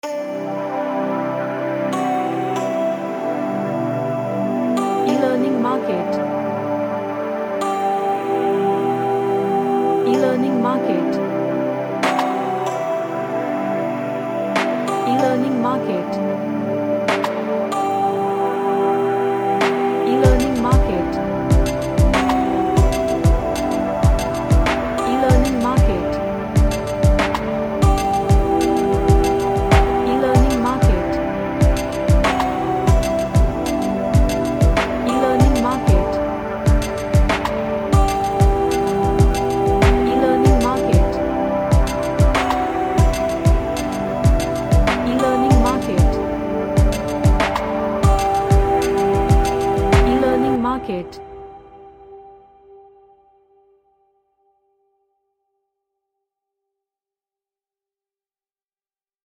A Relaxing ambient track with vocal textures.
Relaxation / Meditation